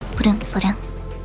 charon vroom vroom Meme Sound Effect
charon vroom vroom.mp3